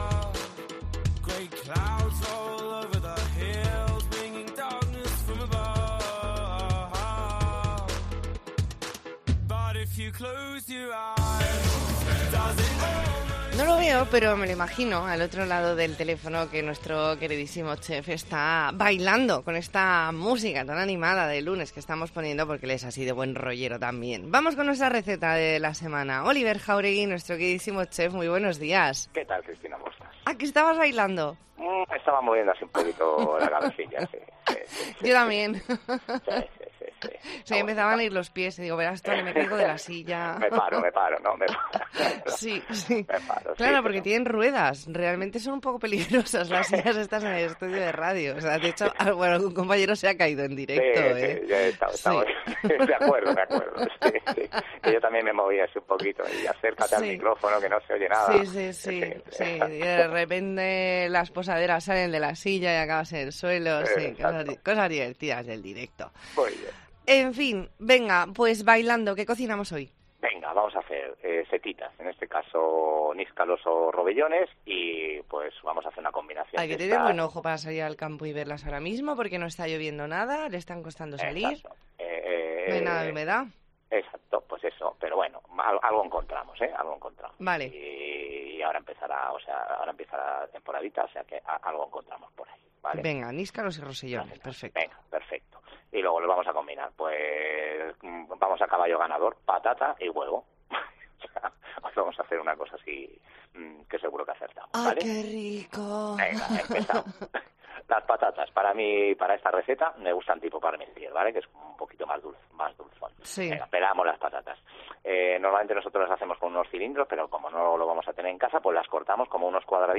Entrevista en La Mañana en COPE Más Mallorca, lunes 24 de octubre de 2022.